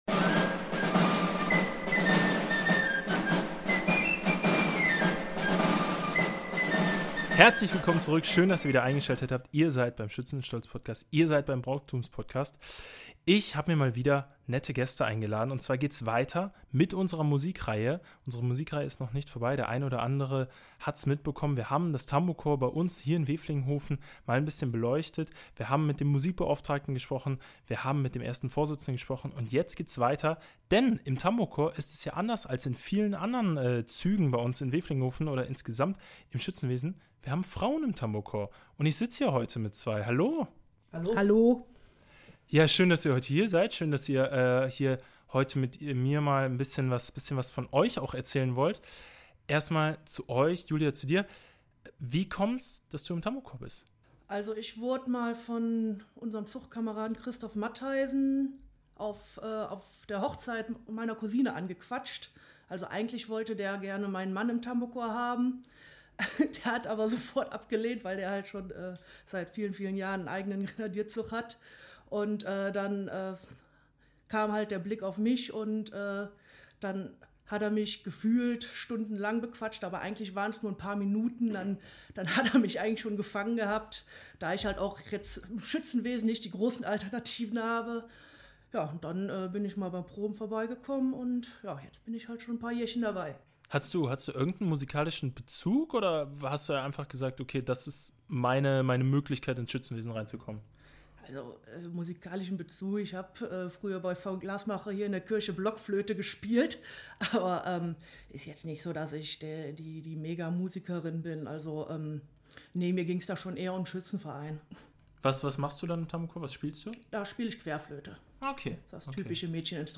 Frauen im Schützenwesen – wie ist es, als Musikerin im Tambourcorps bei den Umzügen durch die Gartenstadt mitzugehen? In dieser Episode erzählen drei Frauen von ihren persönlichen Erfahrungen zwischen Tradition, Kameradschaft und musikalischem Engagement.